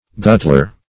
Guttler \Gut"tler\, n. A greedy eater; a glutton.